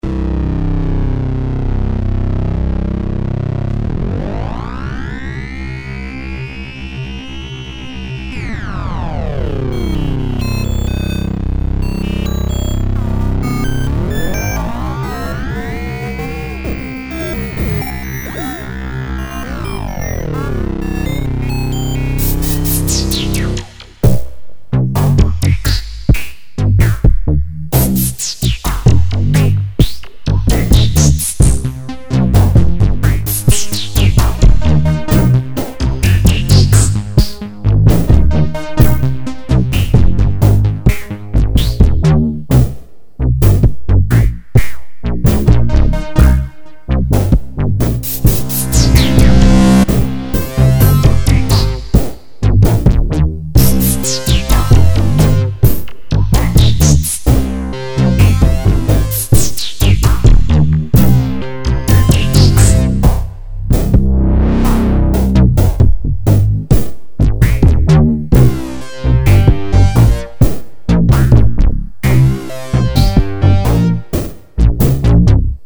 MFB-synth II, mp3 sound samples (192 kbps)
Demo song - Multitracked in computer with panning, reverb and some delay, no other effects.
All sounds come from the MFB-synth II, even drums and percussion.